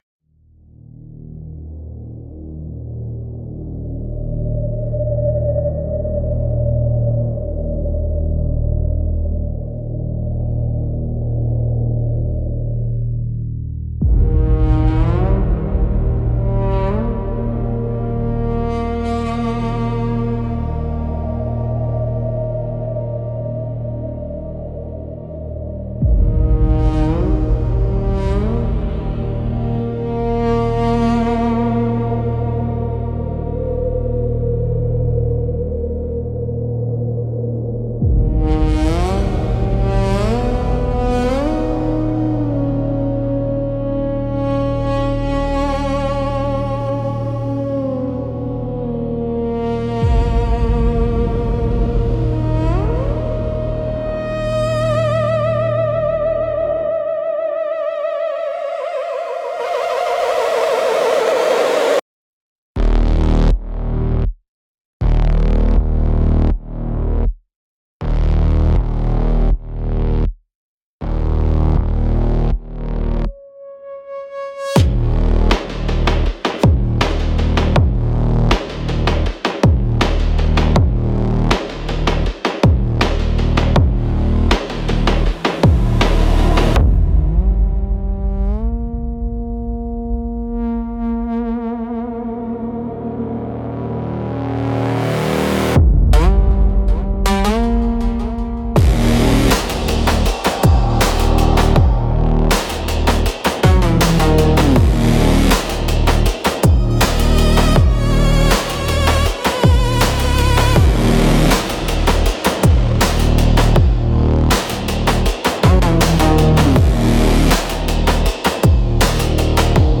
Instrumental - A Gritty Unraveling 4.24